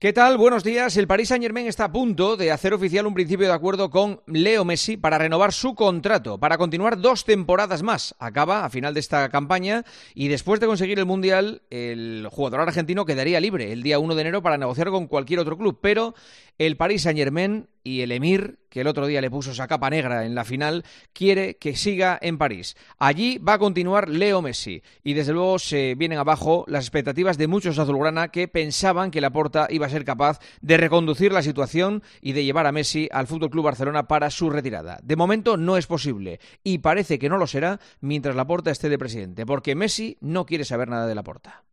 El director de 'El Partidazo de COPE' analiza la actualidad deportiva en 'Herrera en COPE'